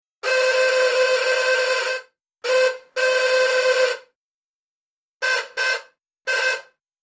Door Buzzer 3; Electric Buzzes; One Very Long Buzz Into Several High-pitched Throaty Buzzes. Close Perspective.